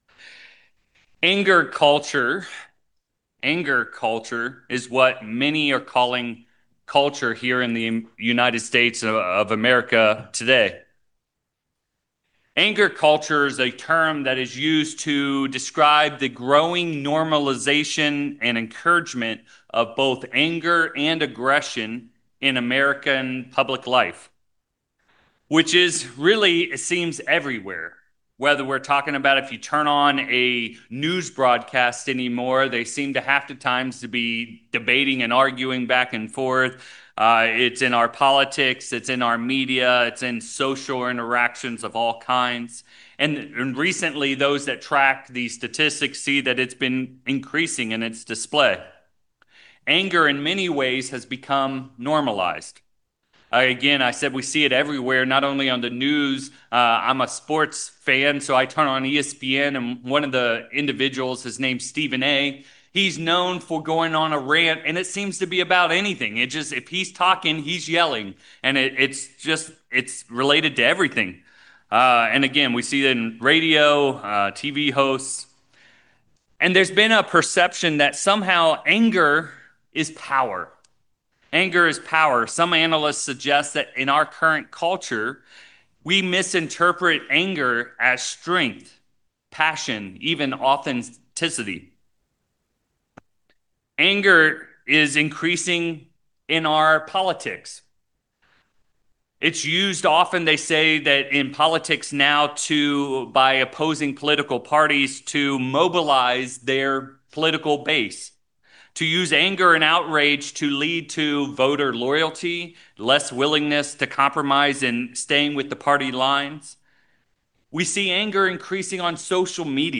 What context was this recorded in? Given in San Francisco Bay Area, CA Petaluma, CA